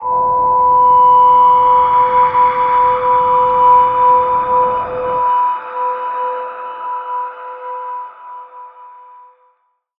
G_Crystal-B5-pp.wav